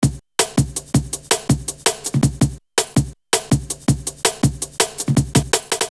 3_DrumLoops_5.wav